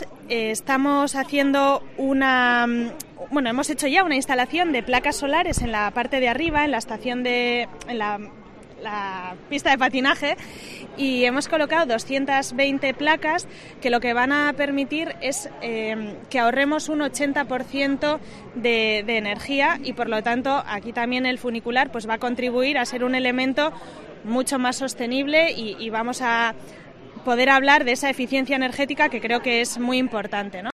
Nora Abete, frente a la estación del funicular de Artxanda
Nora Abete, concejala de movilidad de Bilbao